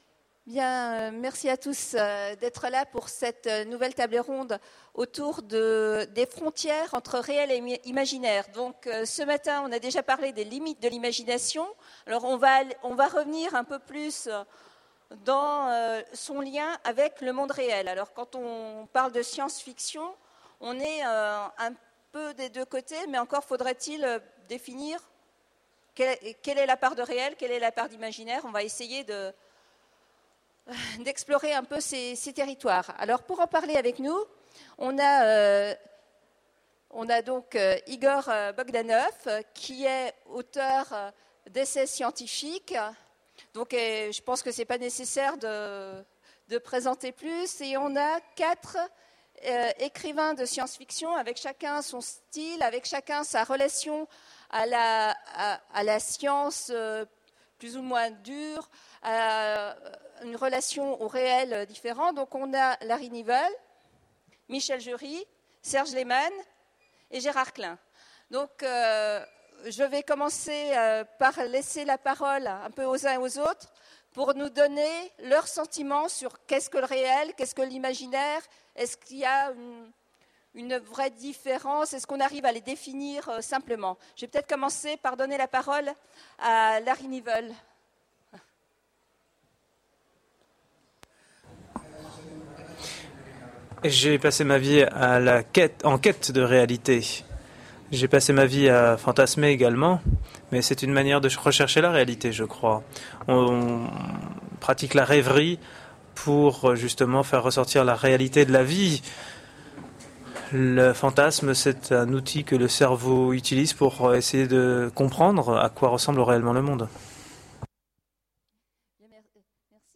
Utopiales 2010 : Conférence Imaginaire, réel, quelles frontières, quelles passerelles ?
Voici l'enregistrement de la conférence " Imaginaire, réel, quelles frontières, quelles passerelles ? " aux Utopiales 2010. Le réel et l’imaginaire s’opposent-ils vraiment ? La science-fiction, en s’inspirant du réel, n’approche-t-elle pas la vérité ?